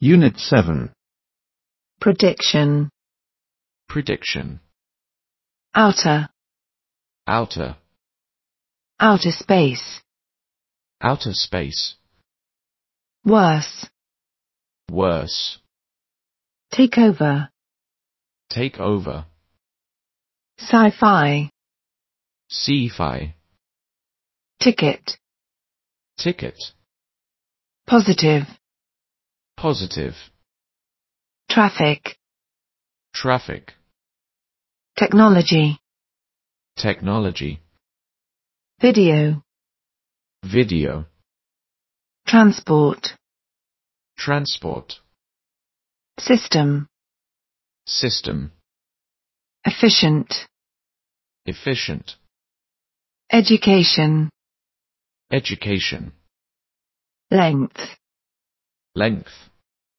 Unit 7 When Tomorrow Comes 单词音频 2025-2026学年人教版（2024）英语八年级上册